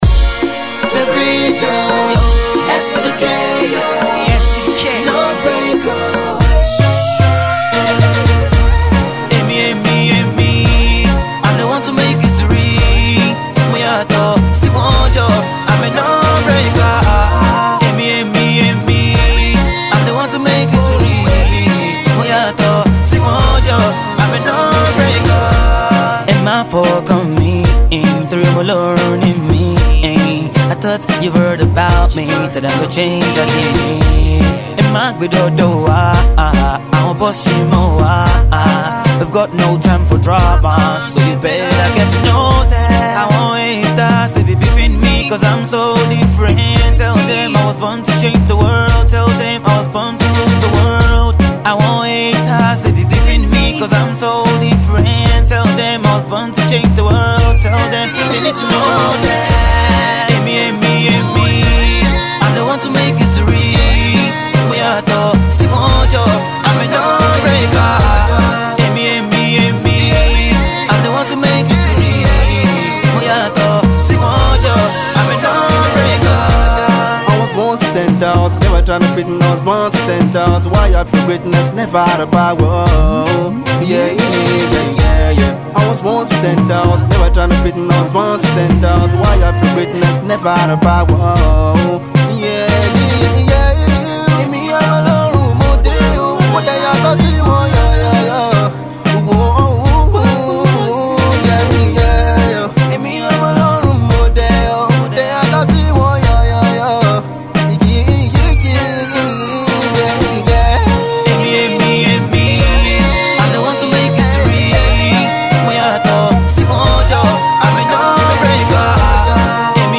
This new Nigerian Afro-Pop duo